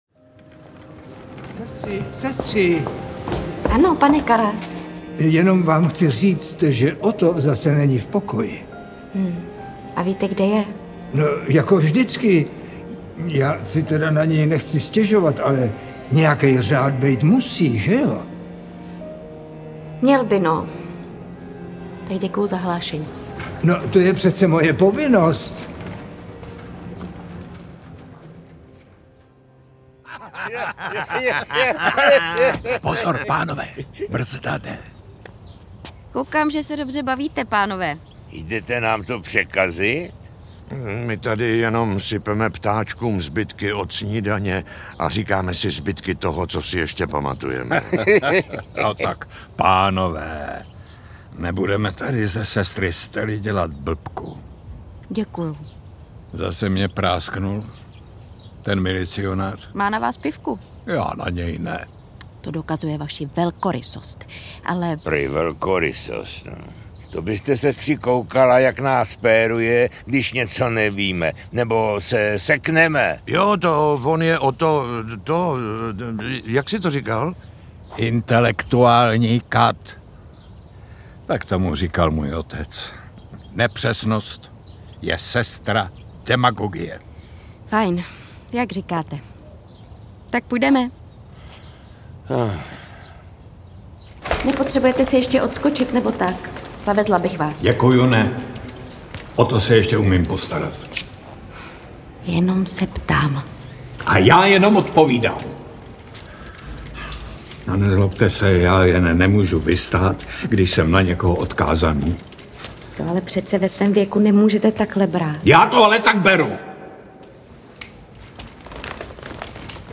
Audiobook
Read: Jaromír Meduna